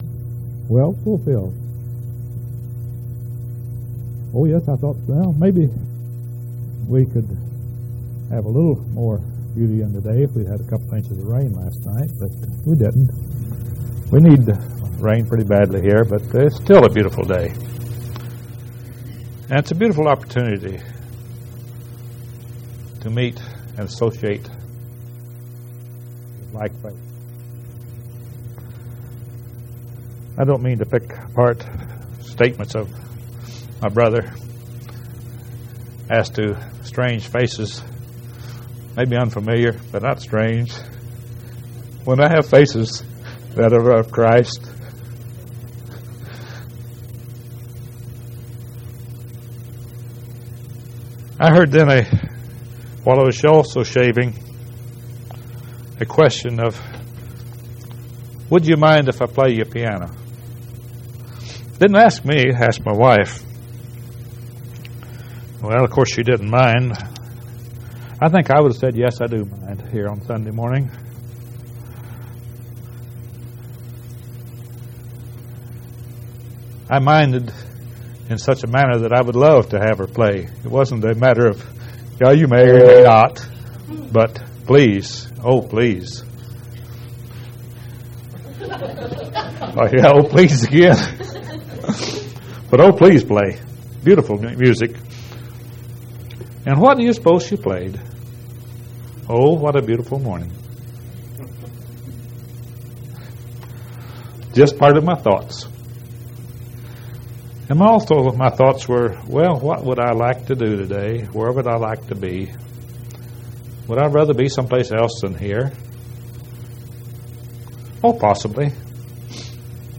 6/12/1988 Location: Collins Local Event